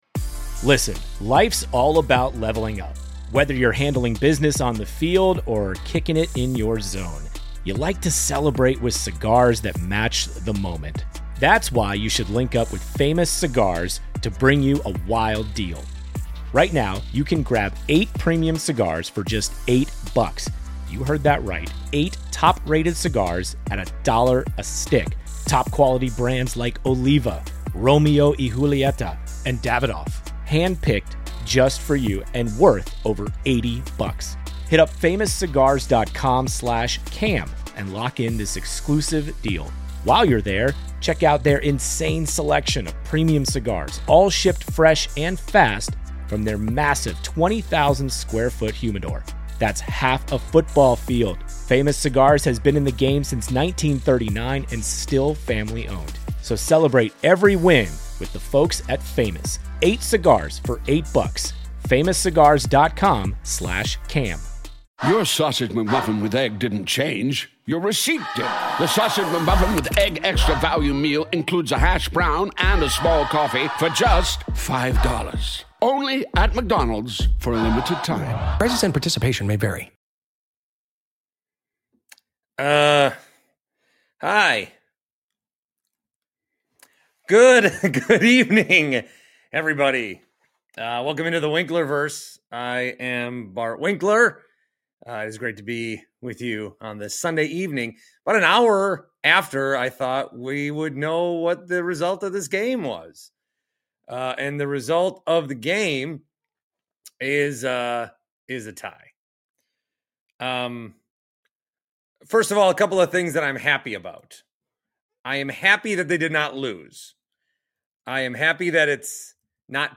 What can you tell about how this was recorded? The gang reacts live after Sunday Night Football Hosted by Simplecast, an AdsWizz company.